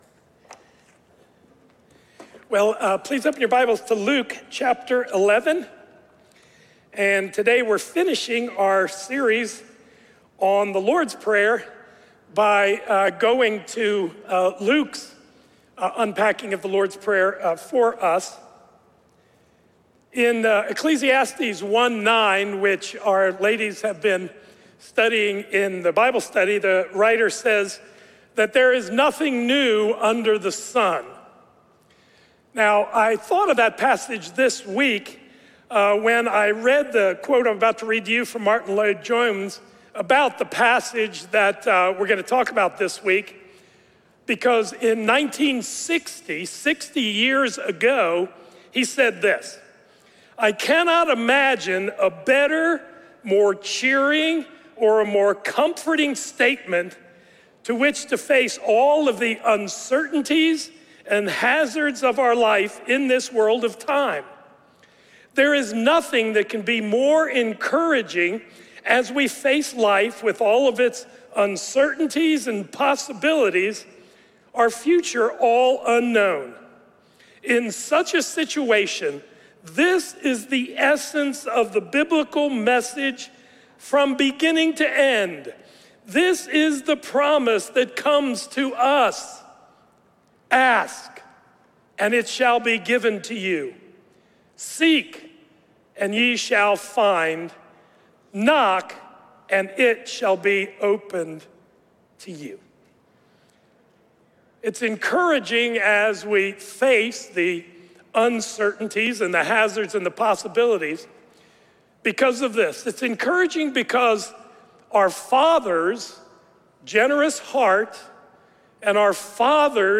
Sunday Sermons – Crossway Community Church